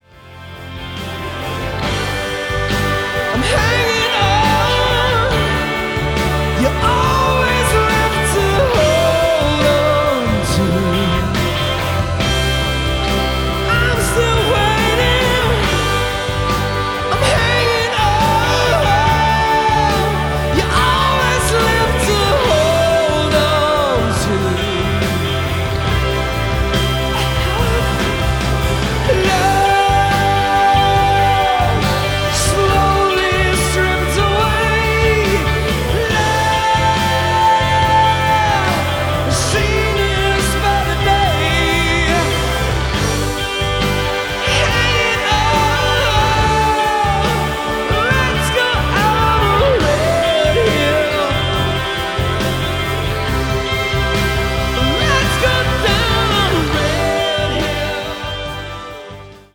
• Качество: 320, Stereo
мужской вокал
80-е
Rock
классический рок